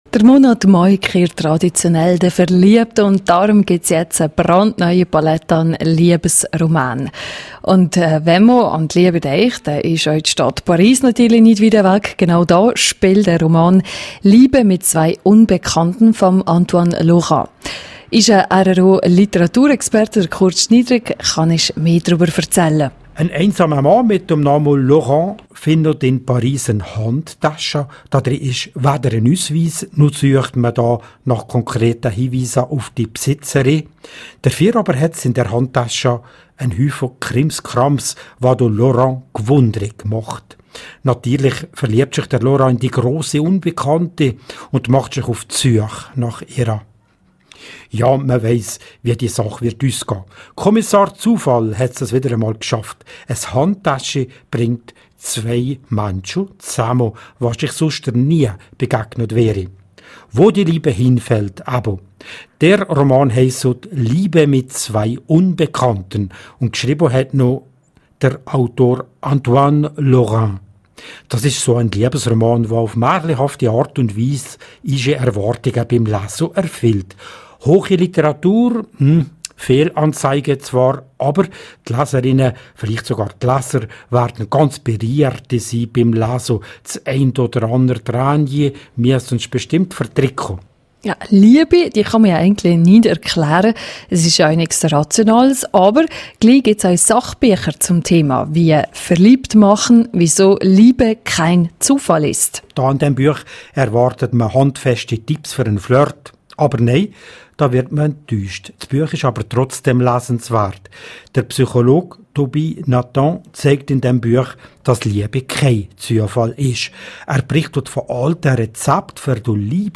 Von und mit
Moderation: